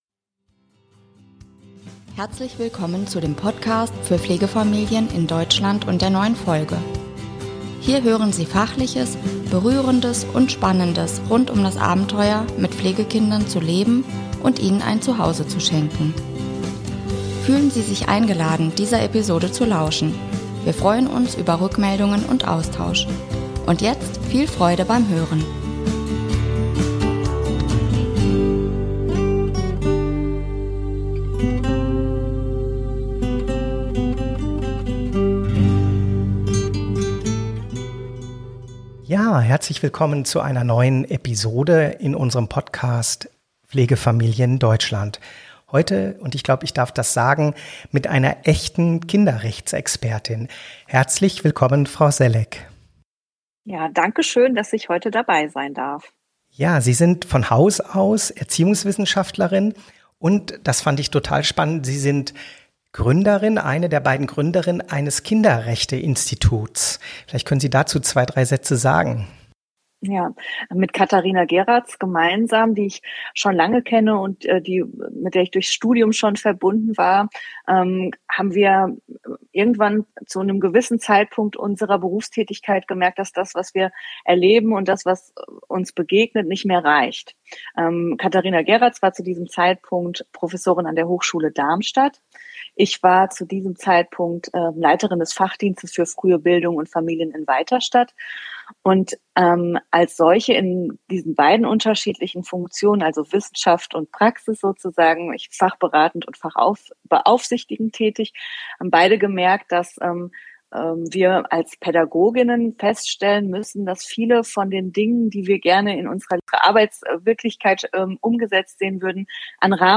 Interview mit: Mir...